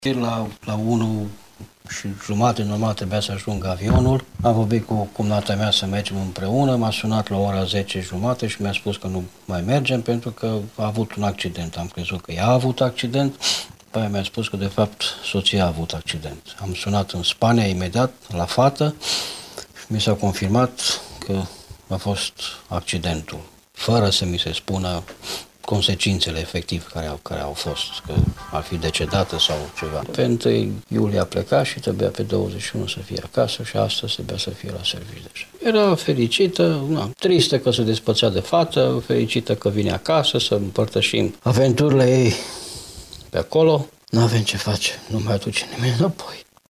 vox-3-accident-spania.mp3